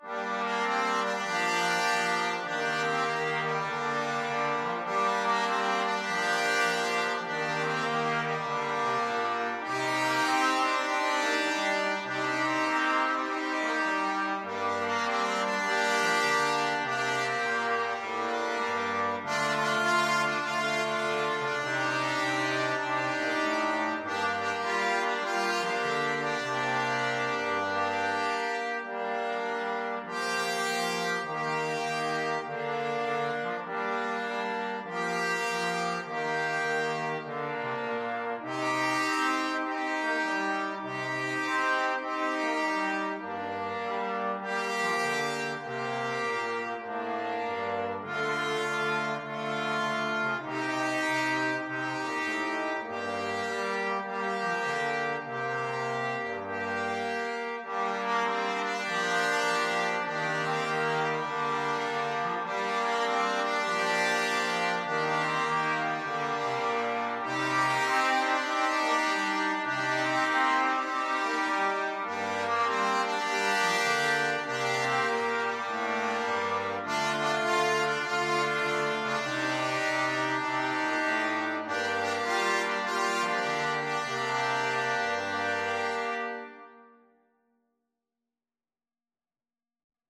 Trumpet 1Trumpet 2French HornTromboneTrombone (Treble Clef)
A simple blues tune with an improvisatory section
4/4 (View more 4/4 Music)
With a swing!
Brass Quartet  (View more Easy Brass Quartet Music)
Pop (View more Pop Brass Quartet Music)